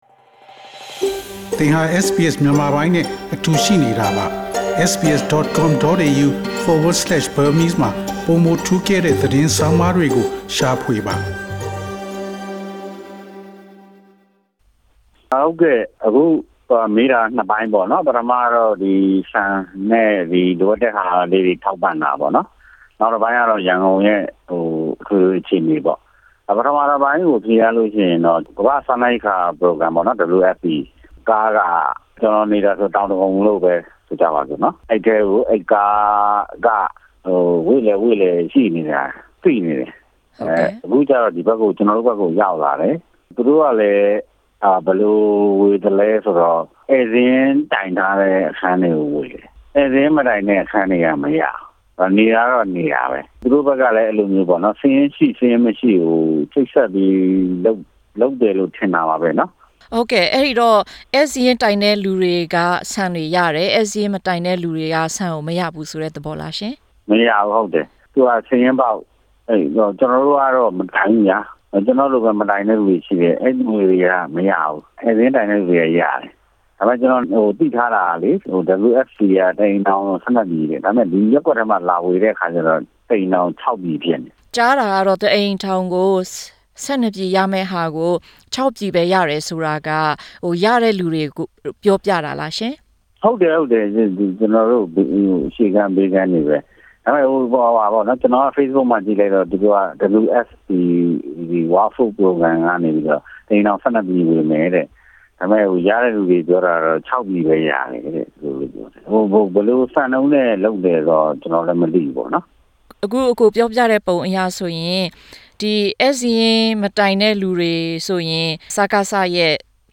အလုပ်အကိုင်ရှားပါးတဲ့အတွက် ငွေကြေးအခက်အခဲလည်း ကြုံနေရ့ ရန်ကုန်မြို့မှာ WFP ကမာၐဘ့စားနပ်ရိကၐခာ အစီအစဥ်ကနေ ဆန်တွေ‌‌‌‌ပေးပေမပေ ဧည့်စာရင်းတိုင်တဲ့လူတွေသာ ရရှိတဲ့အ‌ကြောင်းနဲ့ အခုလောလောဆယ် ကြုံတွေ့နေကြရတဲ့ အခက်အခဲတွေအကြောင်း ရန်ကုန်မြိုံခံတစ်ဦးရဲ့ ပြောပြချက်ကို နားဆင်််််််နိုင်ပါပ့ီ။